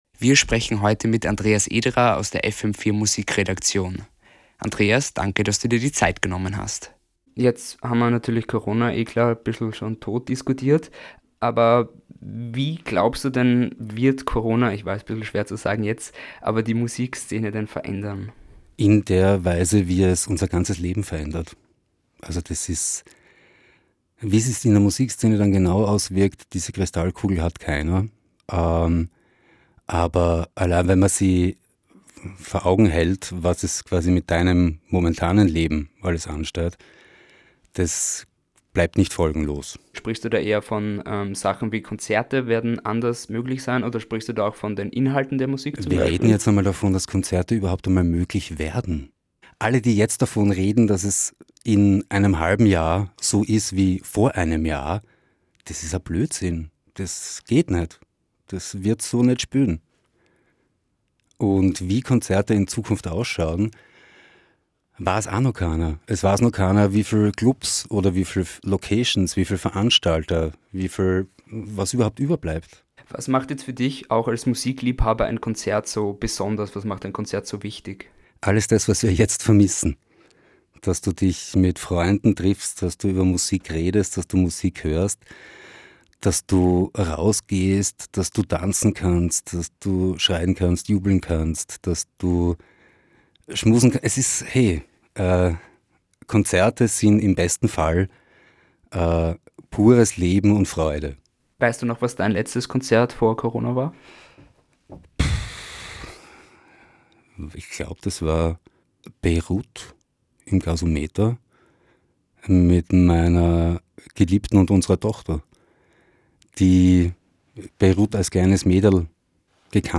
Studiointerview